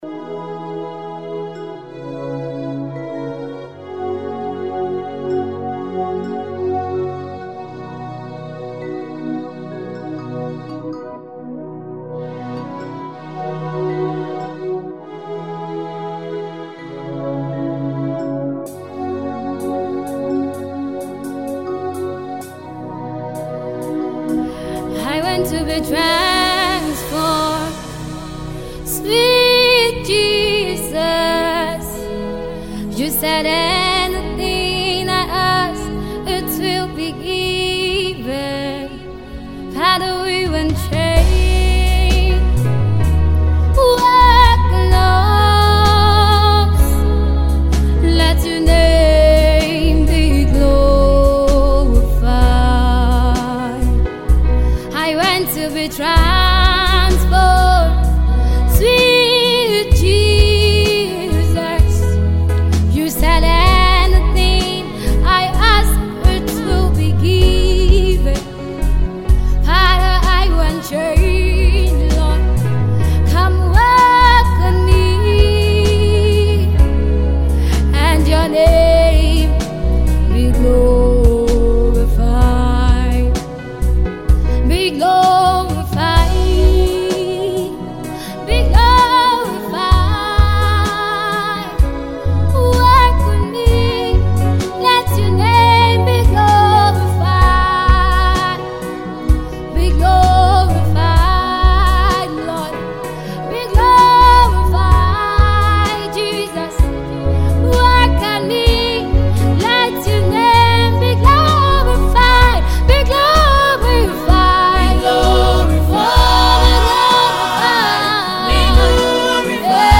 Fast rising Nigerian Gospel Music Minister group